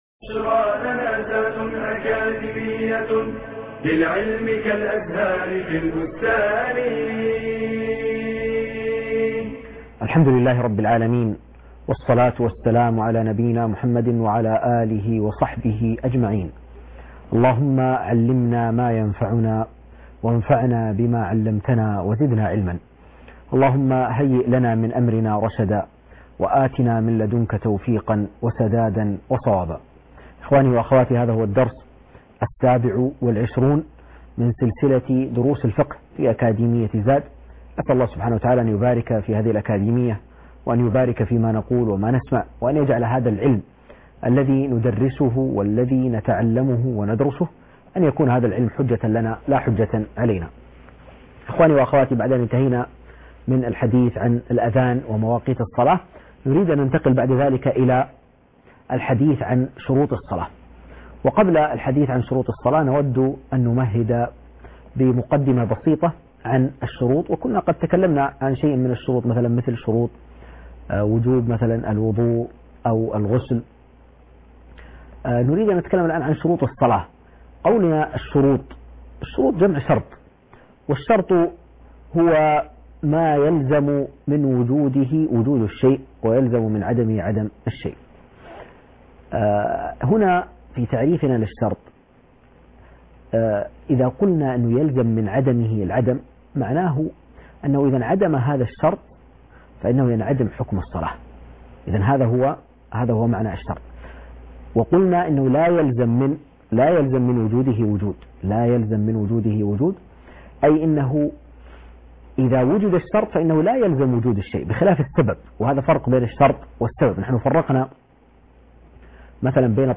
المحاضرة السابعه و العشرين